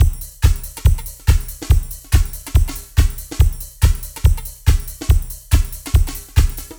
_DR RHYTHM 1.wav